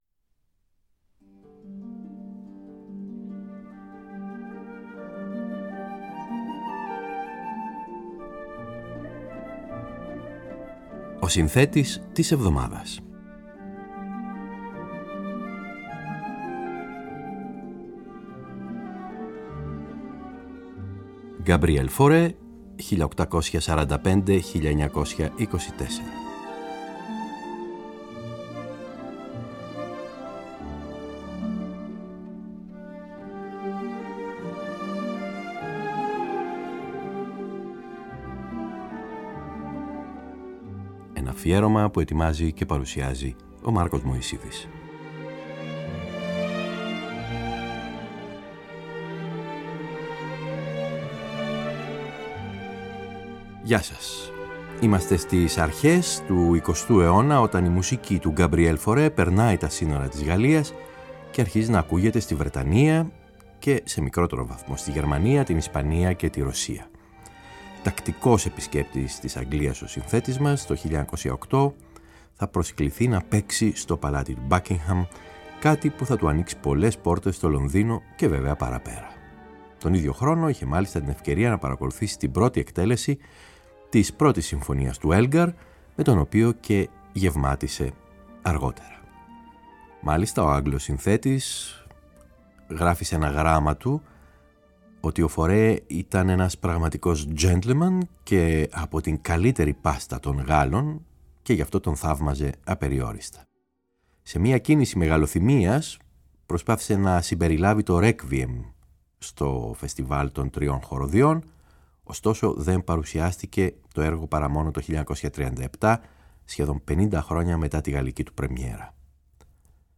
Οι «διάφωνες» συγχορδίες στο έργο του δεν θεωρούνται πια ως τέτοιες, αλλά αποτελούν ηχητικά εφέ, που ο χρωματισμός τους προμηνύει το χαρακτηριστικό ιδίωμα των συνθετών του Ιμπρεσιονισμού. Εν αντιθέσει πάντως με την αρμονική και μελωδική του γλώσσα, που για τους συγχρόνους του ήταν ακραία, το ρυθμικό στοιχείο τείνει να είναι διακριτικό και επαναληπτικό.